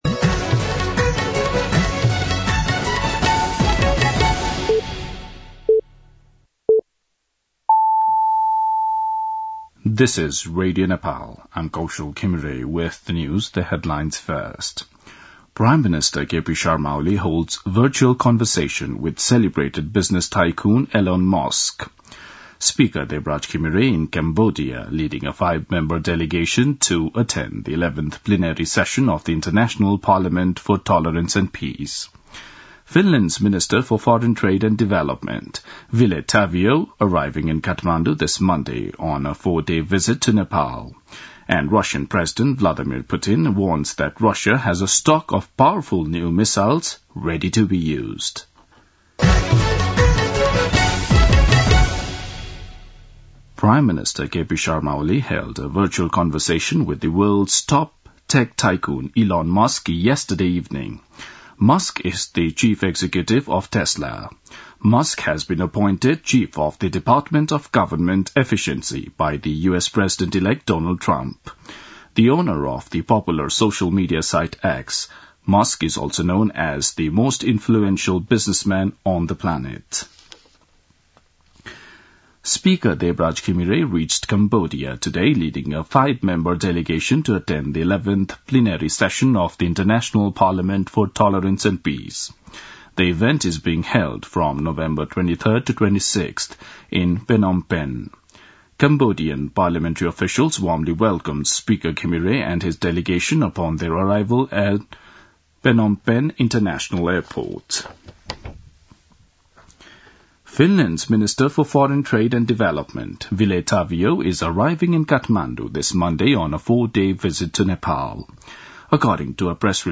दिउँसो २ बजेको अङ्ग्रेजी समाचार : ९ मंसिर , २०८१
2-pm-English-news.mp3